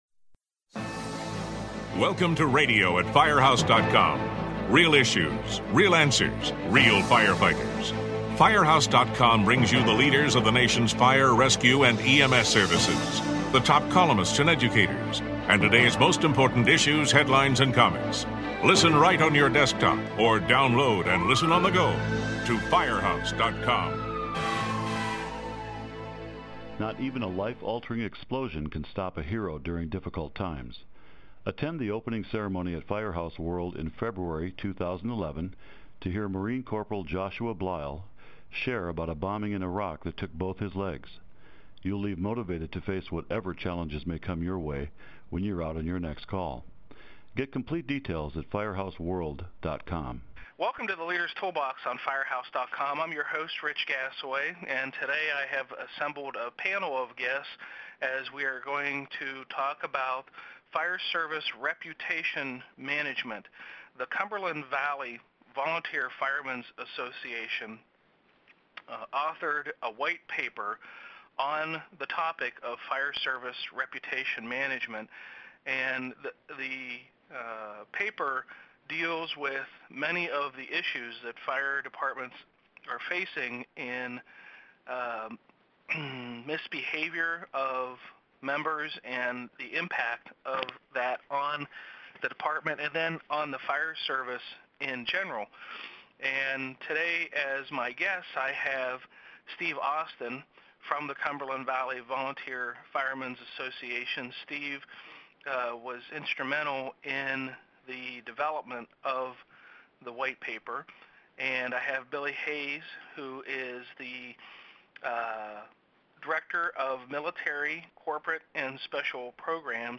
The group looks at several cases where an entire fire department thrust into the spotlight because of a single firefighter's irresponsible actions. They discuss the actions that firefighters and officers carry out to assure that responders are seen in a positive light.